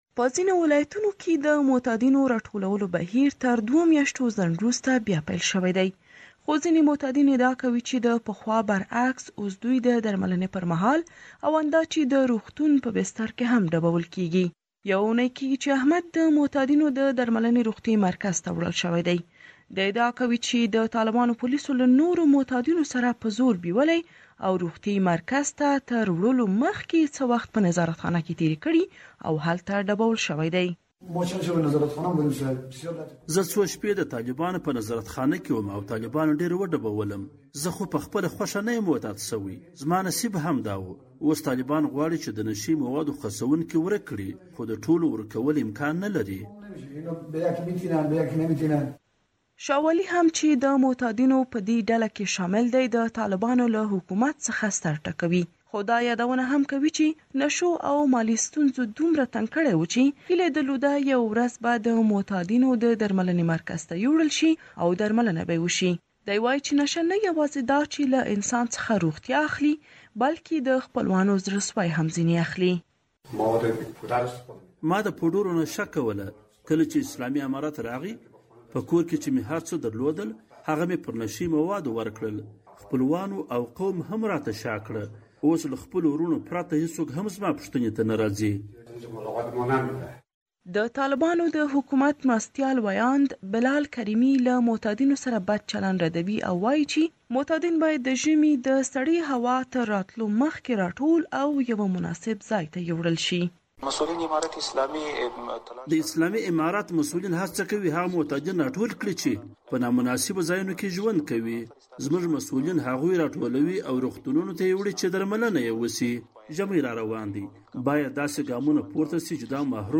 فیچر